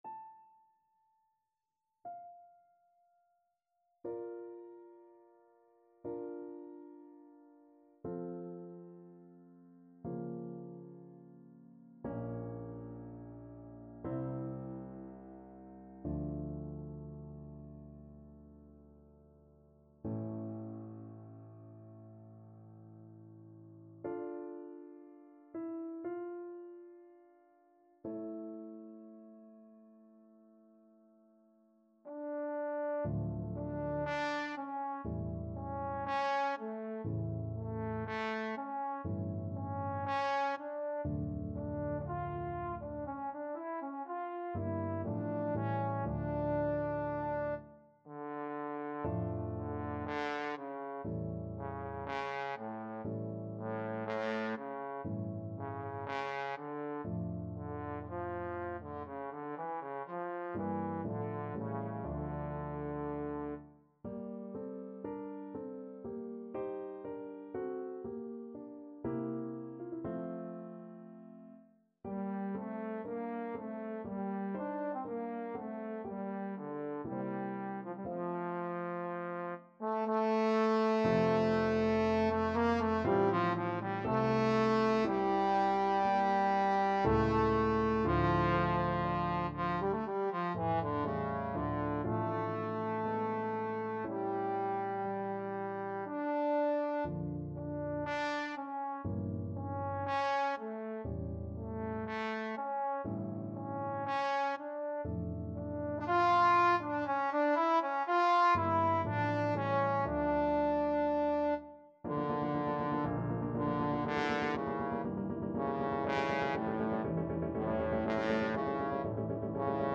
Trombone version
4/4 (View more 4/4 Music)
Adagio non troppo
Classical (View more Classical Trombone Music)